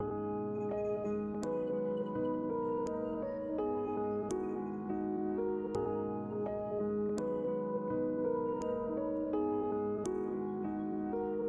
标签： 167 bpm Trap Loops Synth Loops 1.93 MB wav Key : Unknown FL Studio
声道立体声